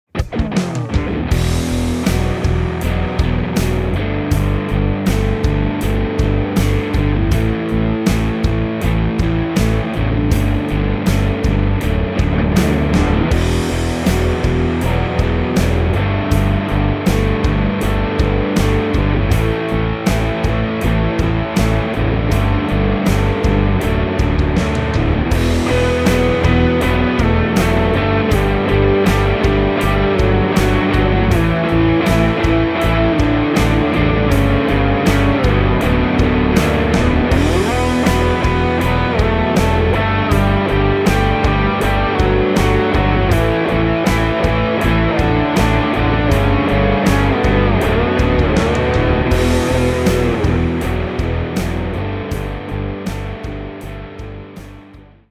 So hier ist das Song-Snippet ohne LeadVox, höchste Konzentration auf die Gitarren. Links die Strat mit Pepper Fuzz Rechts am Anfang das 12 saitige Rickenbacker Arpeggio via Dr. Robert Pedal Im Verlaufe des Songs links ein paar Lead Gitarre Einwürfe der Strat mit Pepper Fuzz.
Bevor die Bridge kommt ließ sich das Strat Signal, recht einfach, fast in ein Feedback treiben ab ca. 40s